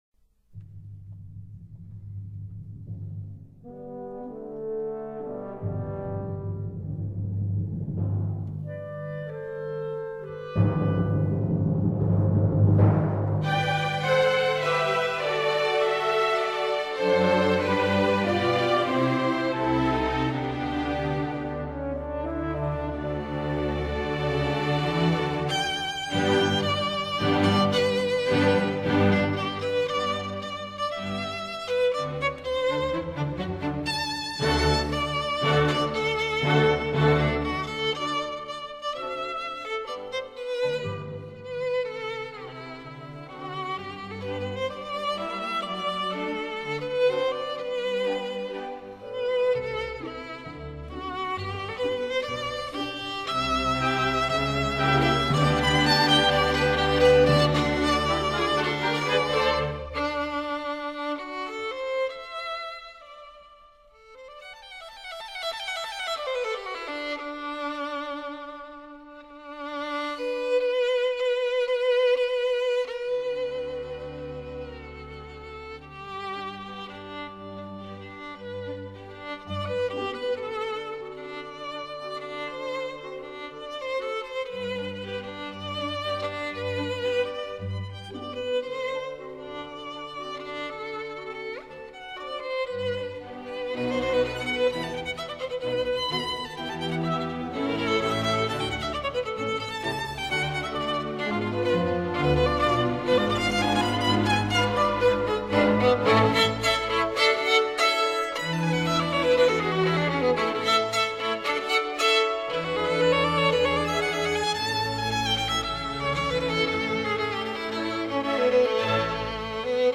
Seitz-Friedrich-student-violin-concerto-No.2-Op.13-for-violin-+-orchestra-128-kbps-.mp3